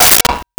Metal Lid 06
Metal Lid 06.wav